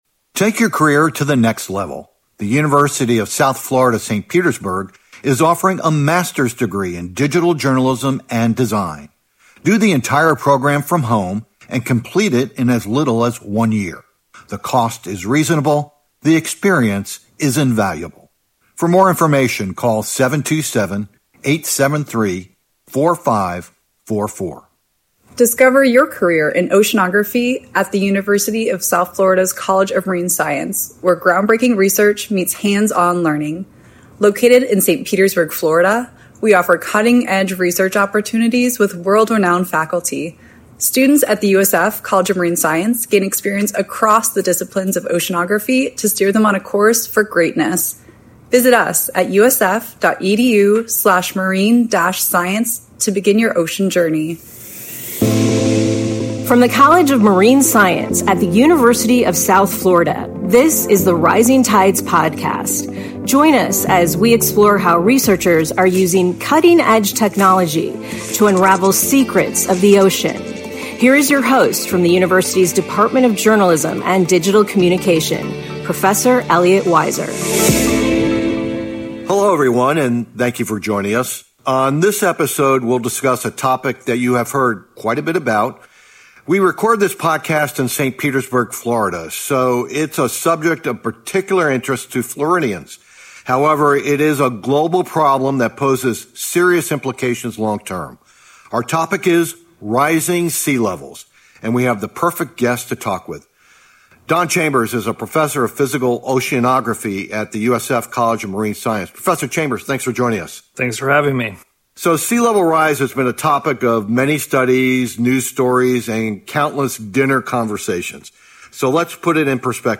ABOUT THE PODCAST This 10-part series dives into oceanographic research and reveals the latest technologies scientists use to understand the ocean. Designed for ocean experts and general audiences alike, “The Rising Tides Podcast” invites oceanographers to discuss their ground-breaking research through one-on-one conversations.